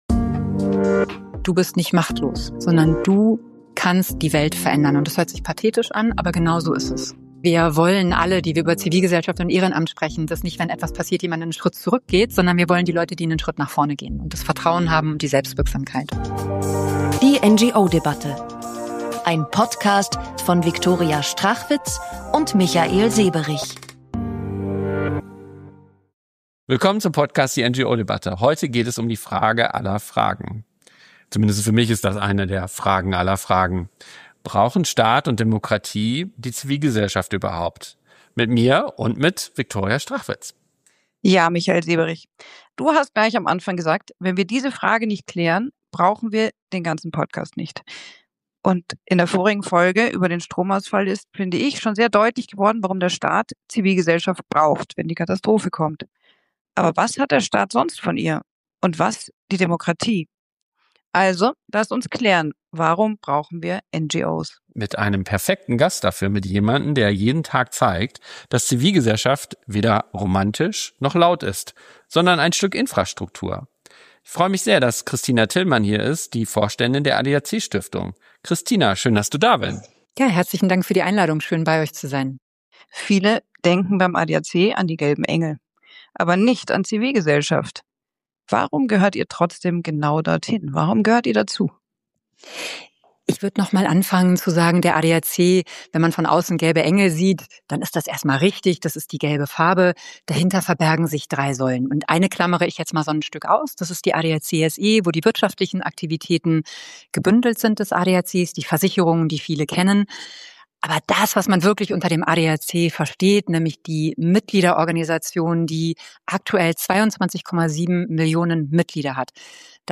Das Gespräch zeigt, wie Demokratie durch Vielfalt, Kontrolle und Mitgestaltung lebendig bleibt – und warum jede Vereins- oder Stiftungsarbeit gelebte Demokratiepraxis ist.